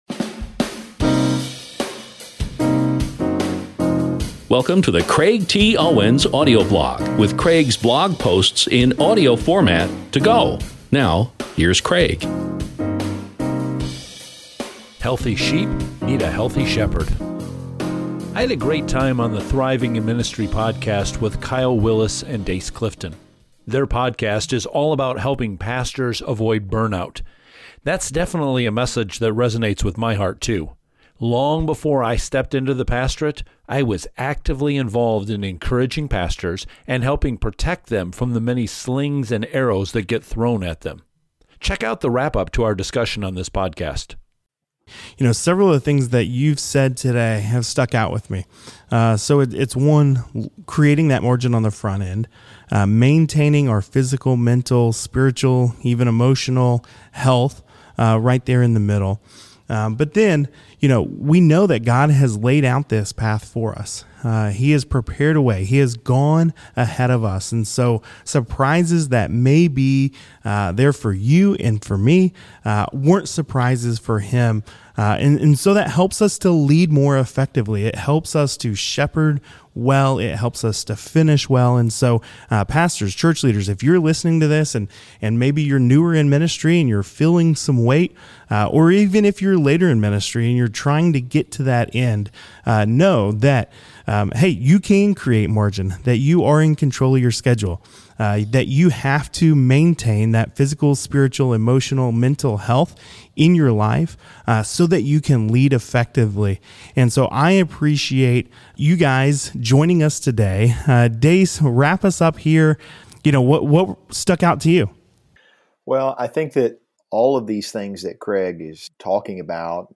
If you’ve missed any of the other clips I’ve shared from this interview, please check them out here: